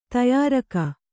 ♪ tayāraka